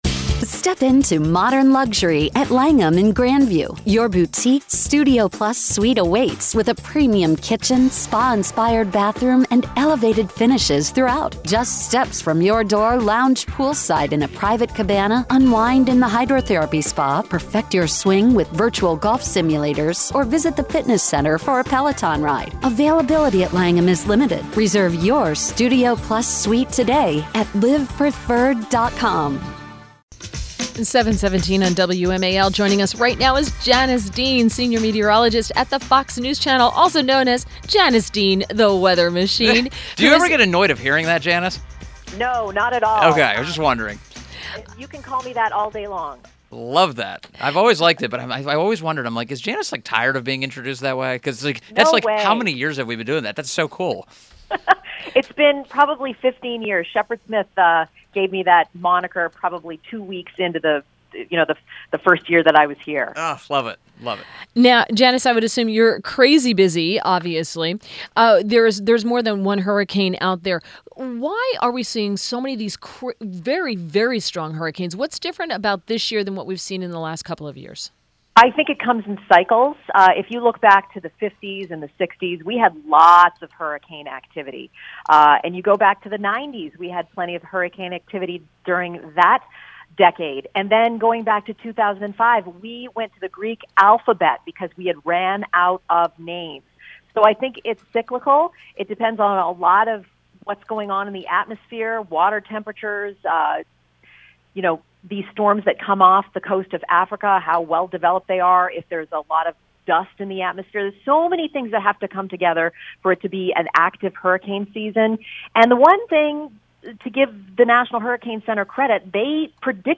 WMAL Interview - JANICE DEAN - 09.08.17
7:15 AM - INTERVIEW - JANICE DEAN - Senior Meteorologist Fox News Channel - latest on the hurricanes